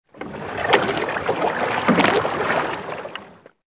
SZ_DD_waterlap.ogg